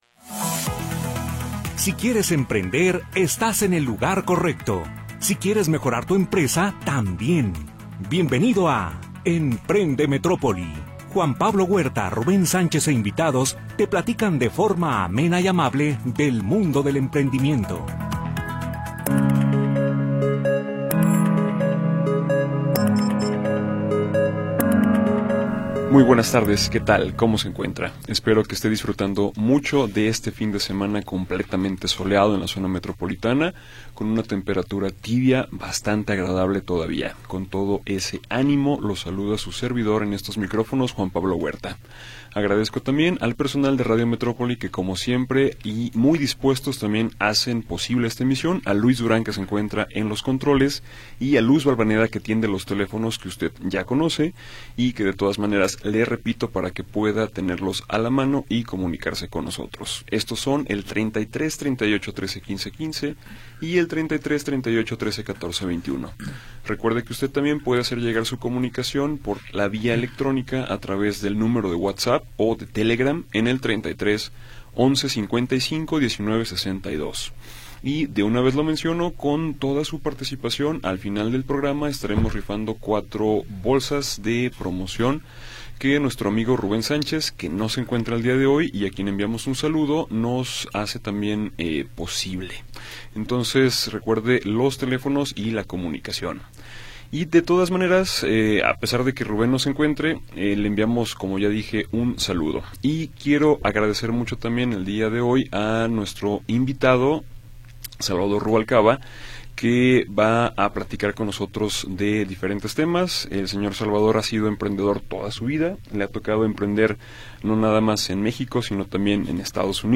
invitados te platican de forma amable y amena acerca del mundo del emprendimiento. Programa transmitido el 22 de Noviembre de 2025.